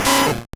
Cri de Rattatac dans Pokémon Or et Argent.